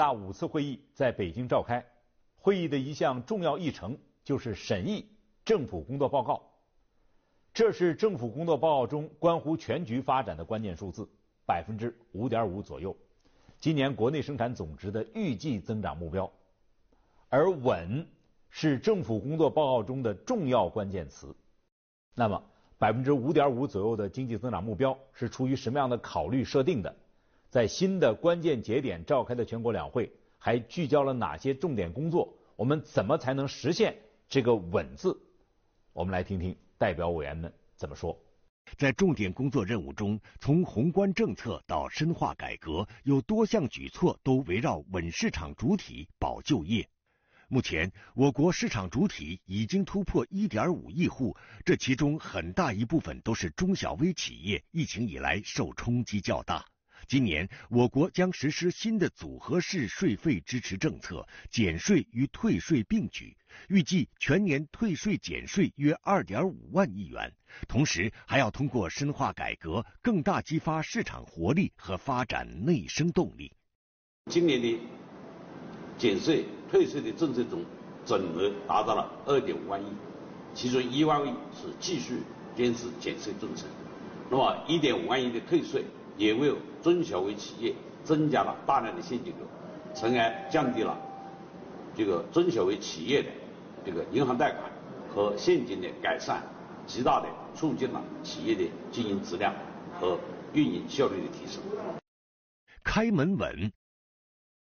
3月6日，曾光安代表在接受中央广播电视总台综合频道《焦点访谈》节目采访时，将目光聚焦到了减税退税对中小微企业、制造业企业带来的利好上。